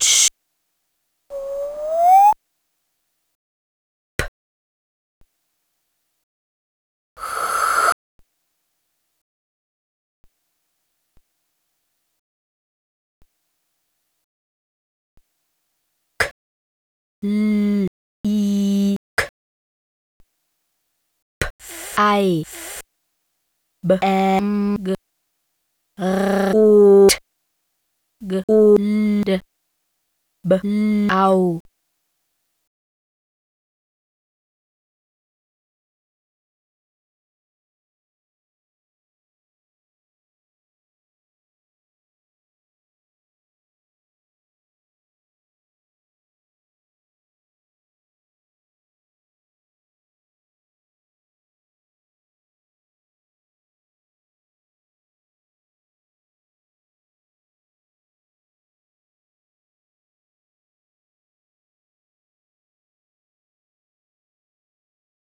phonetic typewriter feuerwerksratekete
Raketen, ausgemalt in Lautzeitlupe.
Ein tierfreundliches Gedicht zum Jahreswechsel nachgereicht, ohne Detonationen und Schreckmomente und trotzdem mit allem, das ein Feuerwerk grundlegend haben muß, vom Pfiff bis zum trockenen Knall.